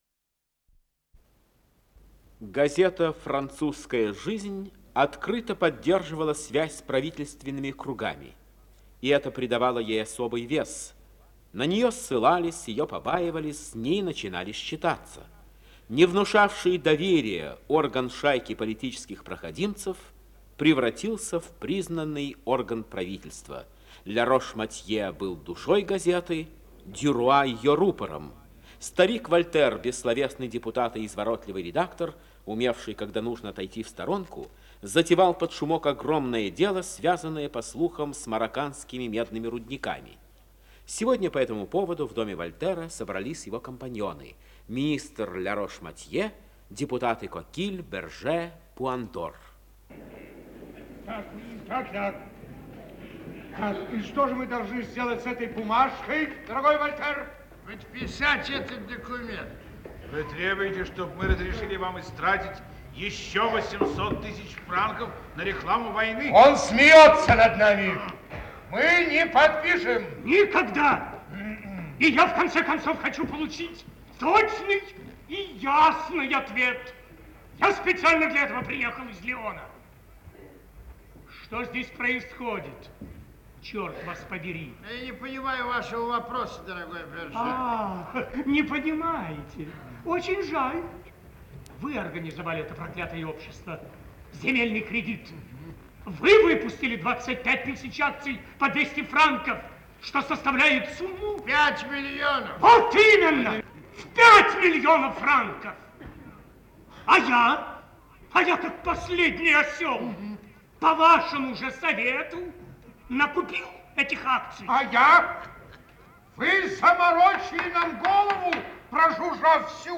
Исполнитель: Артисты московского театра сатиры